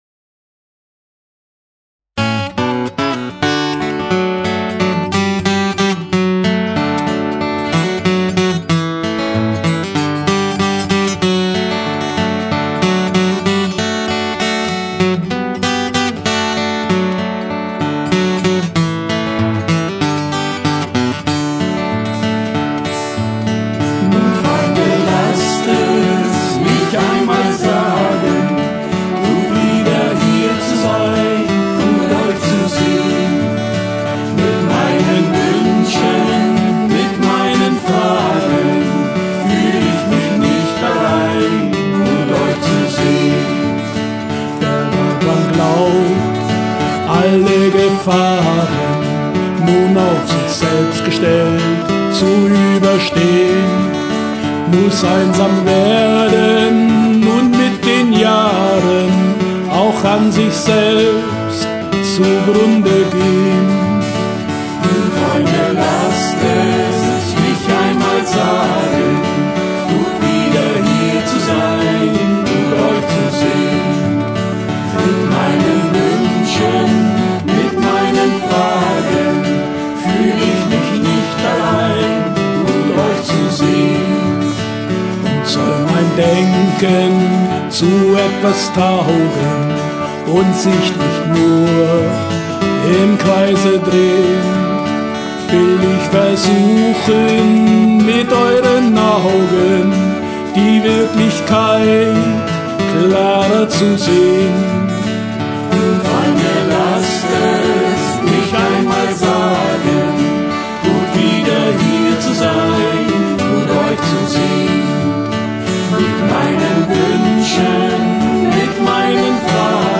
aus der Studio CD